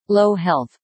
Low_Health.ogg